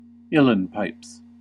En-us-uilleann_pipes.ogg.mp3